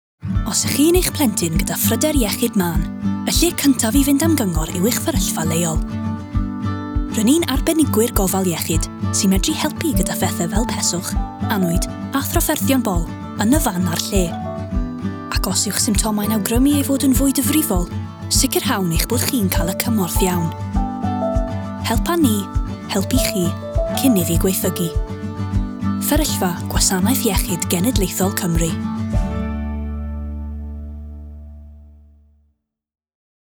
Welsh Language Showreel
Non-binary
Friendly
Playful
Upbeat
Voice Next Door